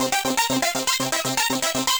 Index of /musicradar/8-bit-bonanza-samples/FM Arp Loops
CS_FMArp B_120-C.wav